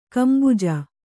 ♪ kambuja